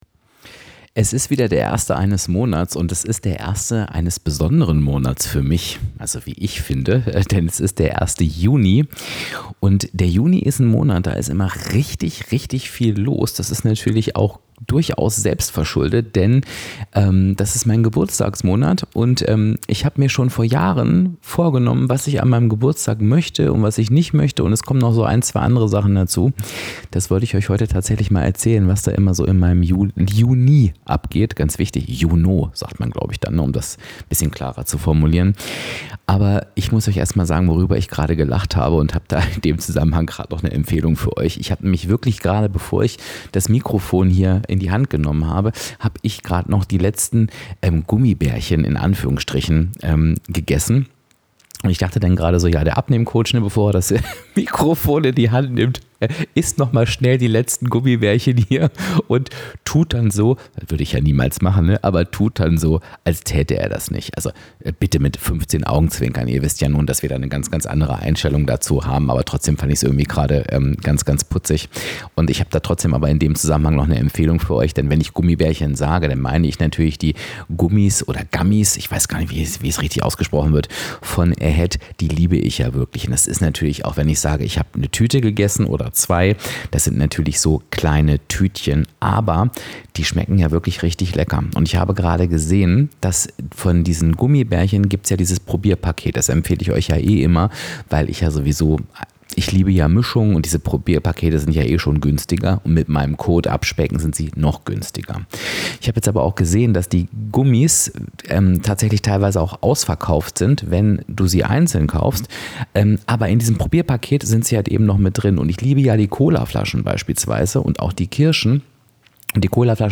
Aber es geht auch um Gesundheit, innere Haltung und die Frage: Wo stehst du dir eigentlich selbst im Weg? Mit Klartext, Anekdoten, echten Einblicken und einem Nieser mitten im Satz Du erfährst u.a.: Warum ich an meinem Geburtstag niemals arbeiten will – und was ich stattdessen tue.